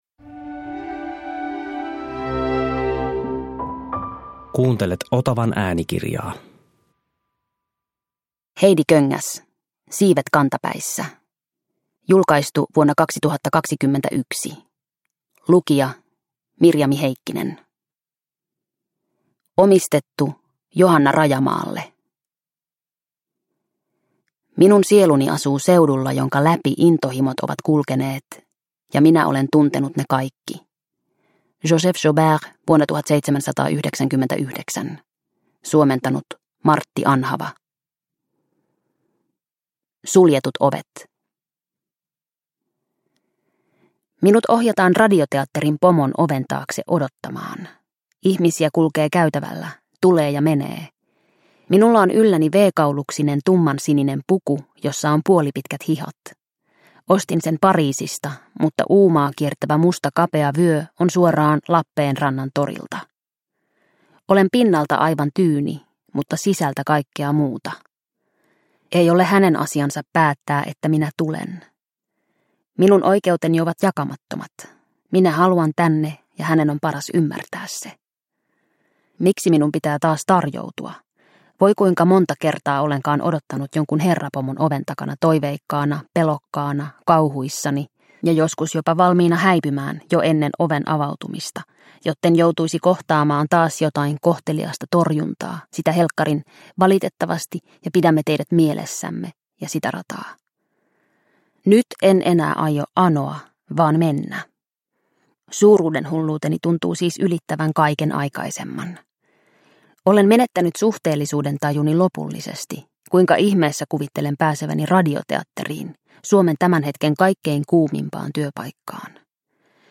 Siivet kantapäissä (ljudbok) av Heidi Köngäs